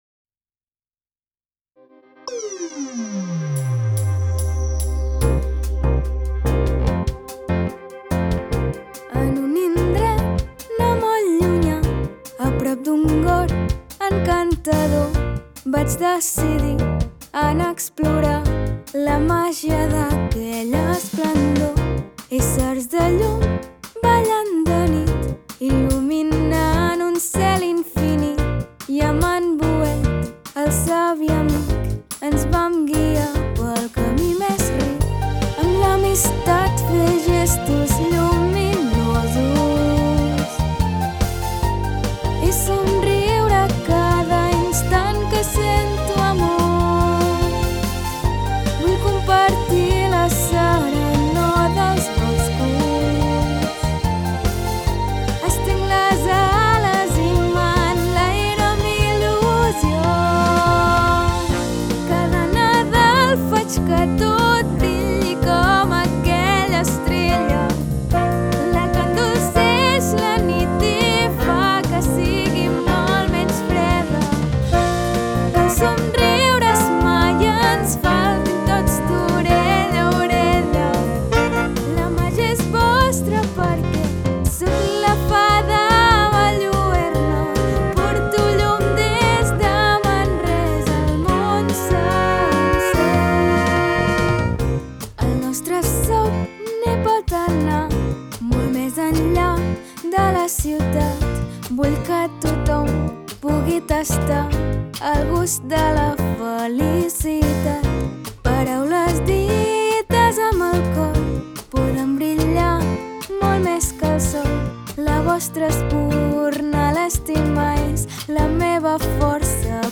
Descarregar Conte Cançó Descarregar cançó En un indret no molt llunyà, a prop d’un gorg encantador, vaig decidir anar explorar la màgia d’aquella esplendor.